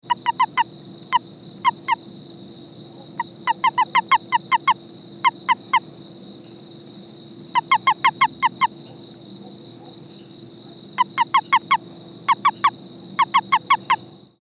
Release Calls
A release call is produced by a male toad or an unreceptive female toad when a frog or other animal (including a human hand) grabs it across the back in the position used for mating or amplexus.
Sound  This is a 14 second recording of the release calls of an Amargosa Toad (sex not determined) made at night in Nye County, Nevada. Insects are heard in the background.